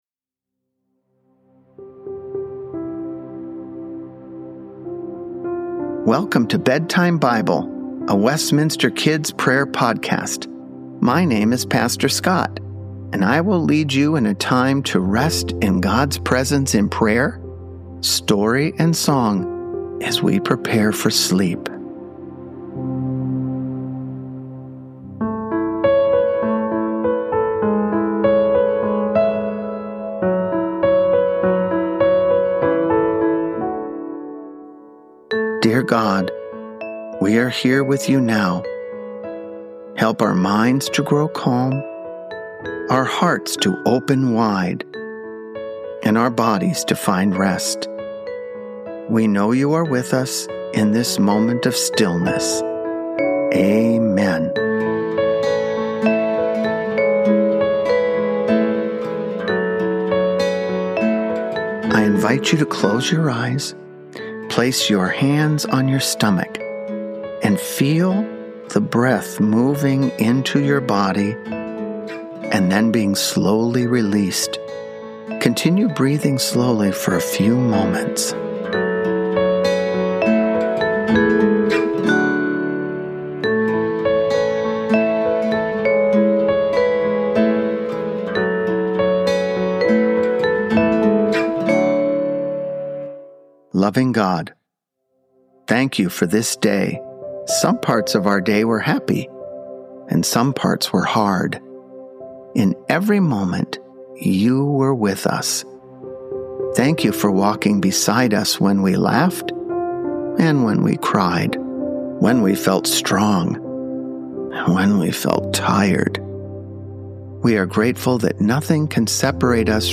Help your children prepare for bed with our prayer and relaxation podcast.  Each podcast will feature calming music, Scripture and prayers to help children unwind from their day.